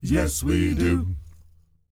ACCAPELLA 1F.wav